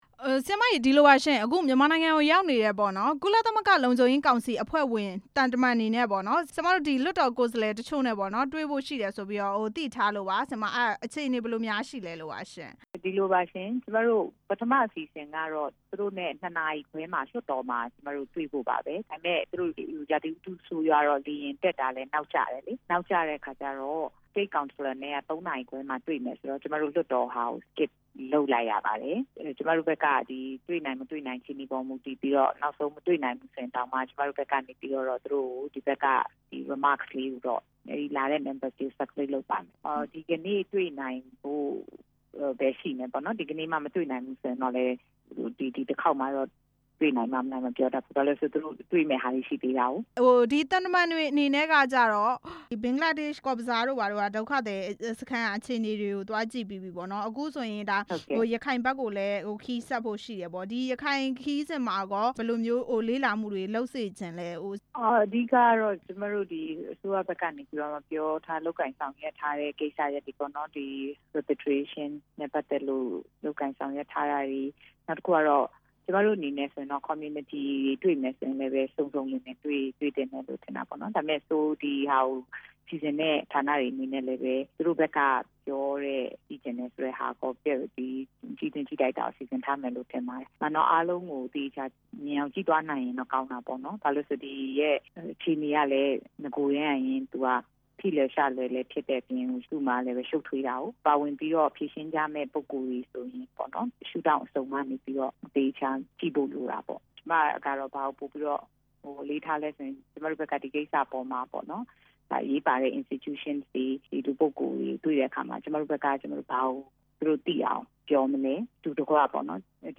ပြည်သူ့လွှတ်တော် ကိုယ်စား လှယ် ဒေါ်ပြုံးကေသီနိုင်နဲ့ မေးမြန်းချက်